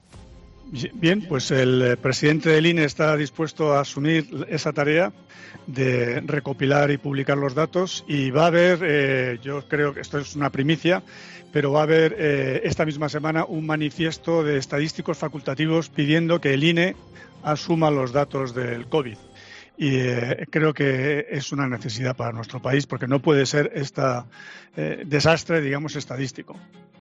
El exministro de Industria, Comercio y Turismo, Miguel Sebastián, en su entrevista de 'Al Rojo Vivo'